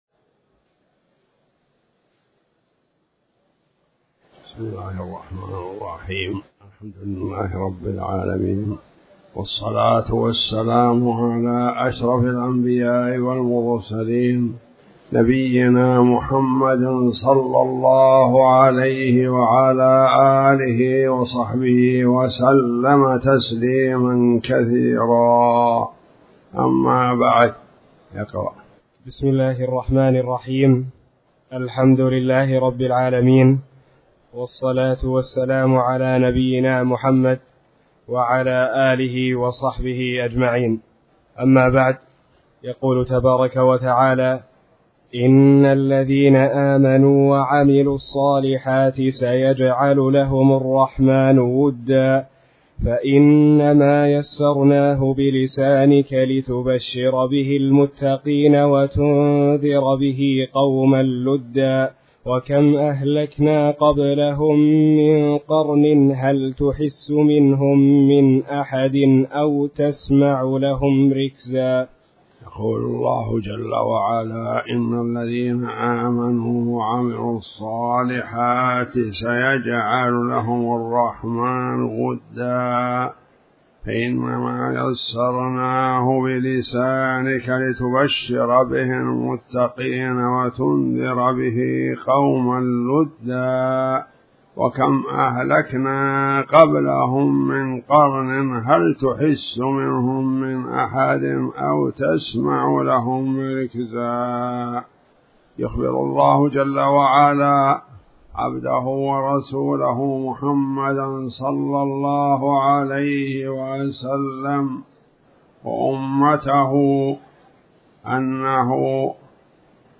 تاريخ النشر ٢٥ ذو الحجة ١٤٣٩ هـ المكان: المسجد الحرام الشيخ: فضيلة الشيخ عبدالرحمن بن عبدالله العجلان فضيلة الشيخ عبدالرحمن بن عبدالله العجلان سورة مريم